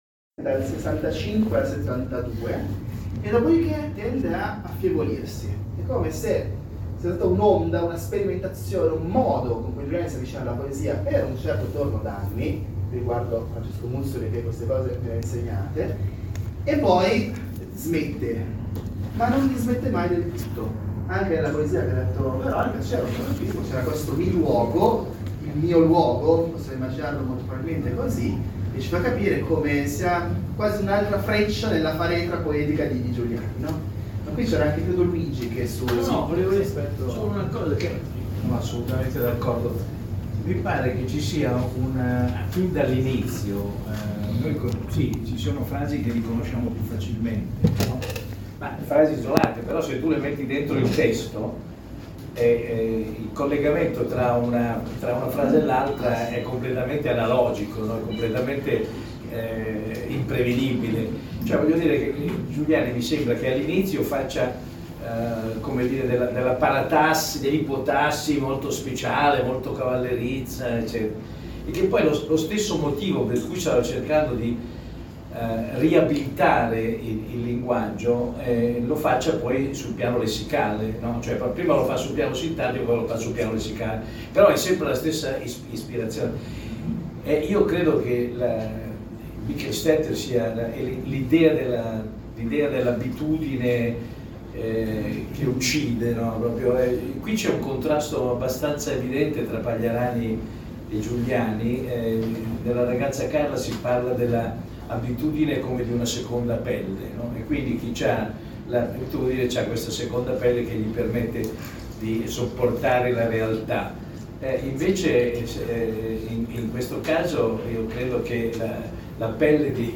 pod al popolo, #048, audio (parziale) della presentazione della raccolta di tutte le poesie di alfredo giuliani (marsilio, 2024) @ libreria tomo, 26 nov. 2024
Non ho potuto catturare che una parte dell’incontro: si può ascoltare qui su Pod al popolo.